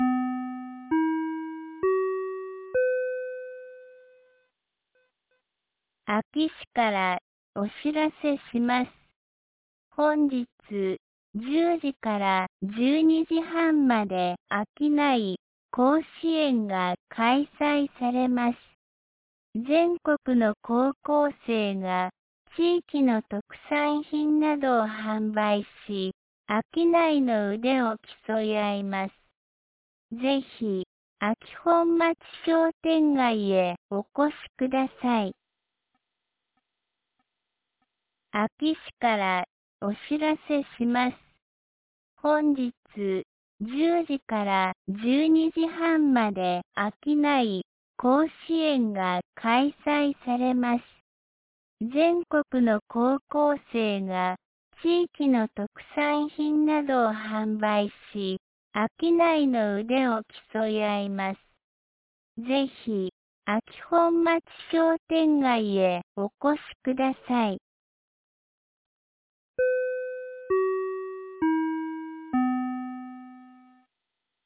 2025年10月26日 09時16分に、安芸市より全地区へ放送がありました。